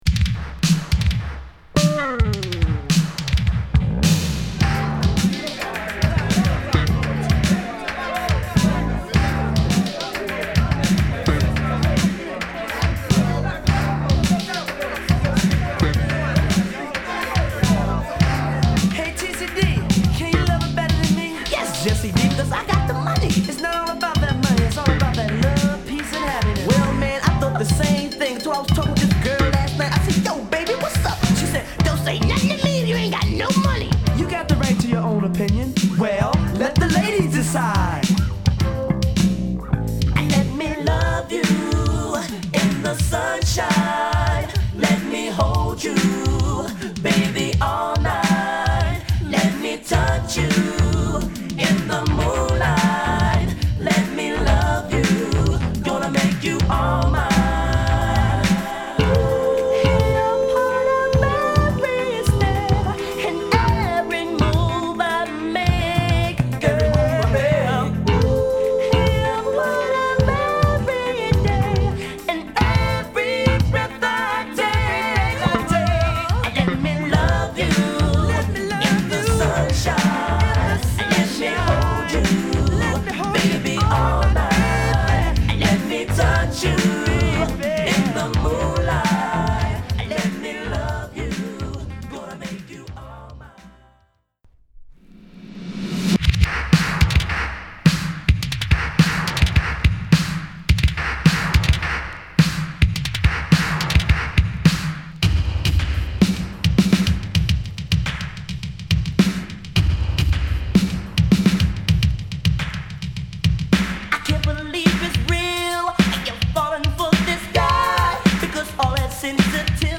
N.Y.スタテンアイランド出身のヴォーカルグループ
NJS〜オールドスクール／エレクトロなビートにキャッチーな美メロコーラスが乗るダンスクラシックス